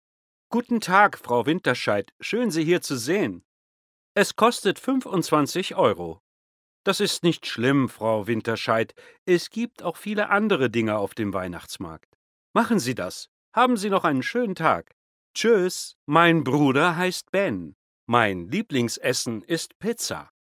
German male voice over